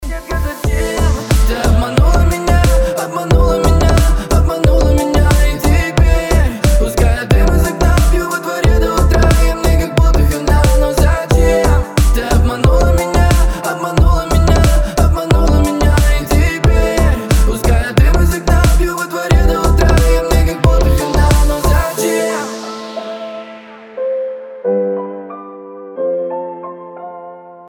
• Качество: 320, Stereo
deep house
грустные
ремиксы